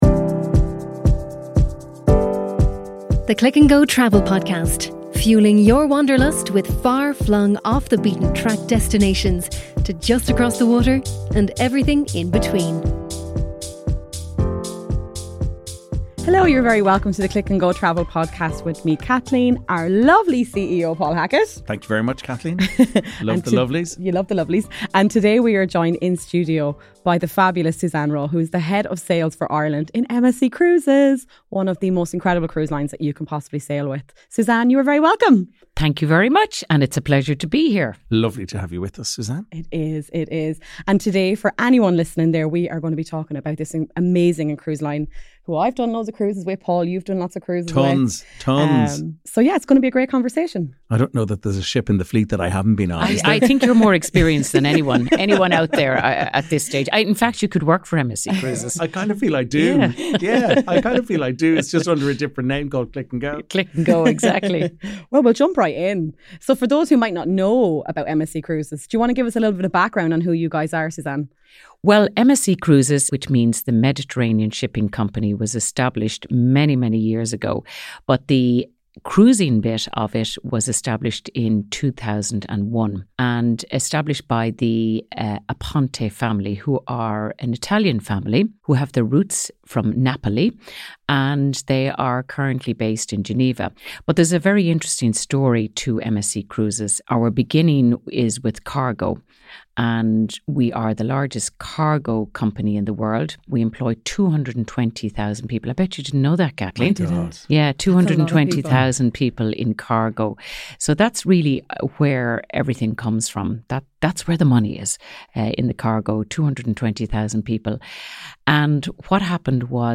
We discuss their heritage and their love for family cruising, we touch on their commitment to the environment, the experiences guests can expect on board and of course, the wonderful destinations you can sail to around the world. This conversation is full of insights, and we hope you enjoy it.